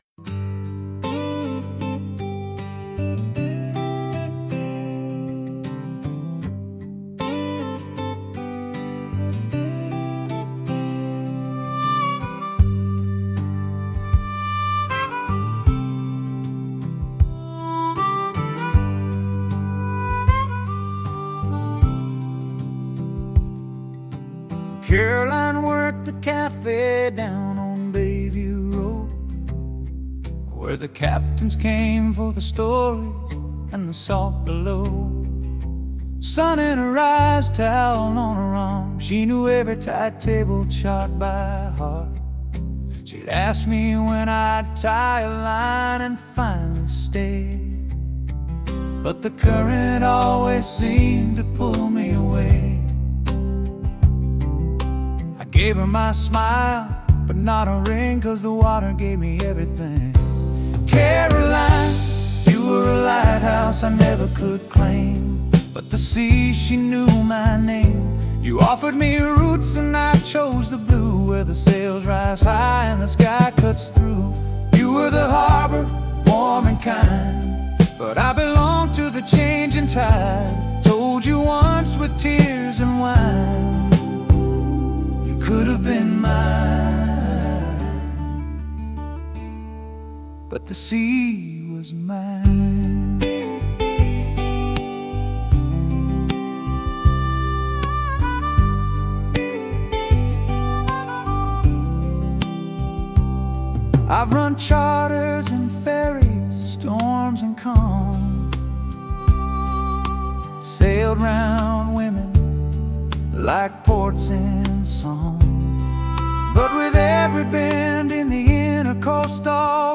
Southern Tropic Rock and Blues Music Creation